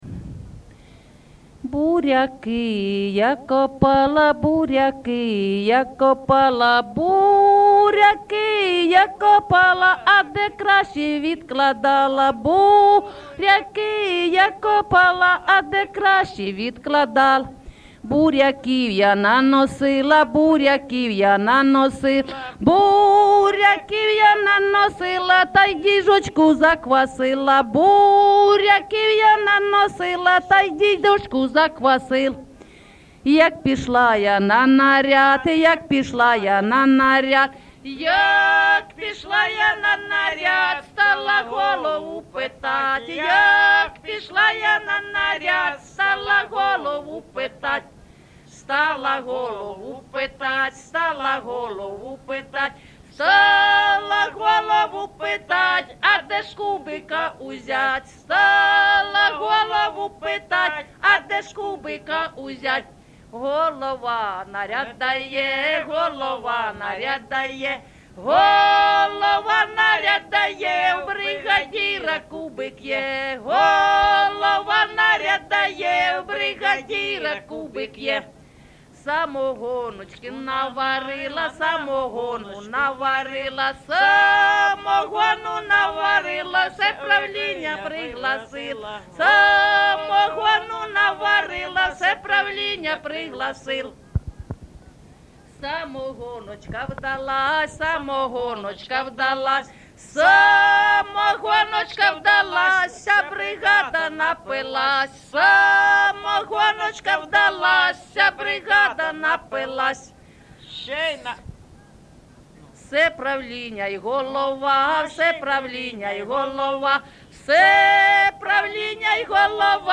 ЖанрПісні з особистого та родинного життя, Колгоспні, Жартівливі
Місце записус-ще Калинівка, Бахмутський район, Донецька обл., Україна, Слобожанщина